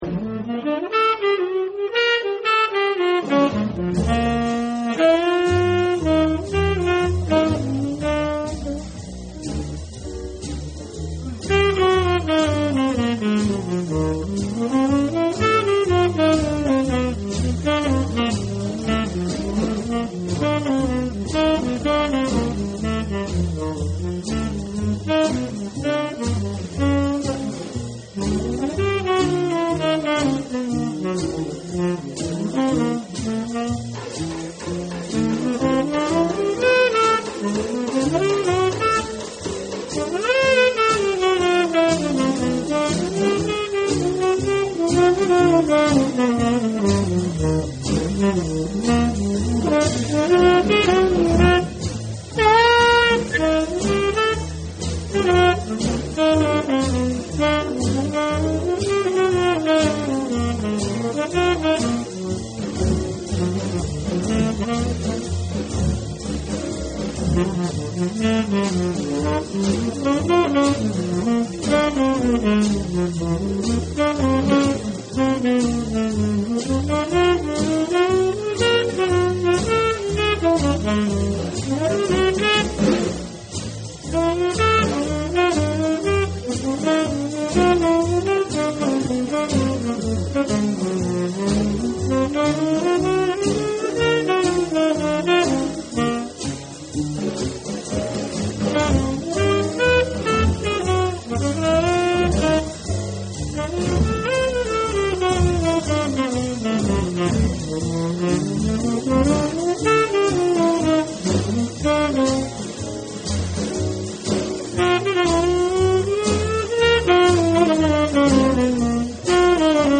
quatre saxophonistes tenors
Deux fois plus lent: